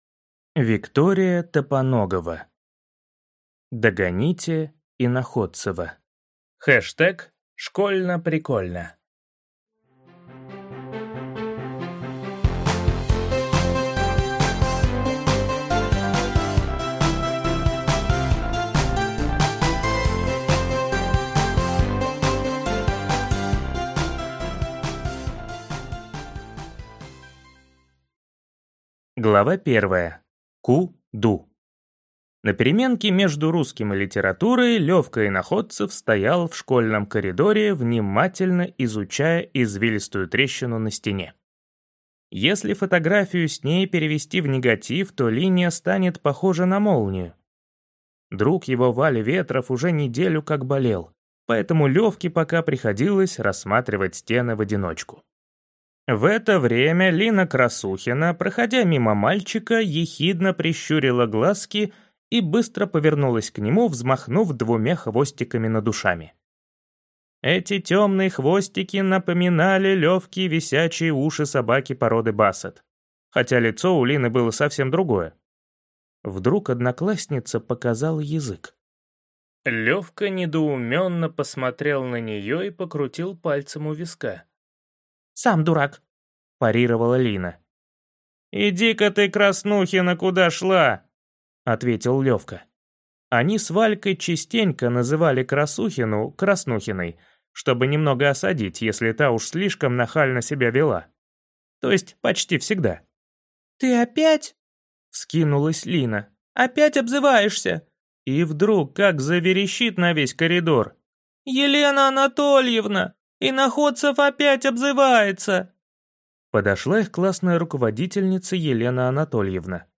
Aудиокнига Догоните Иноходцева!